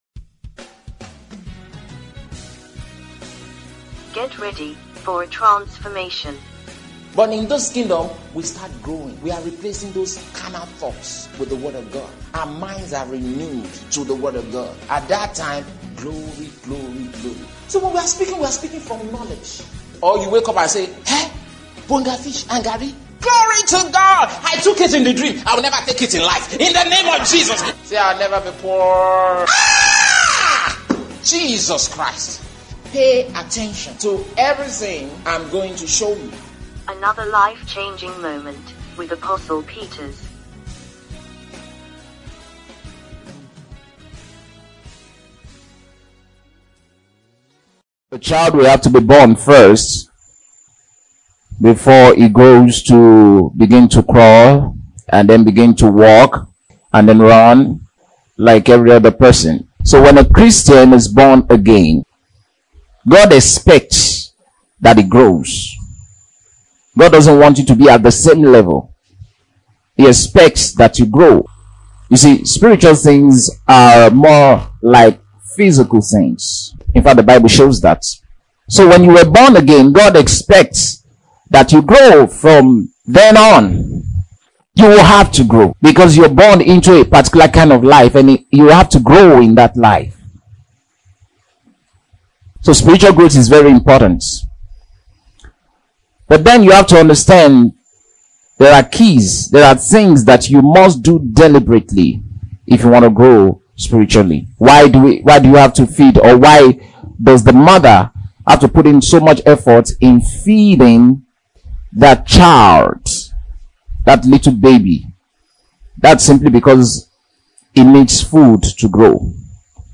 Sermons | AVP Ministries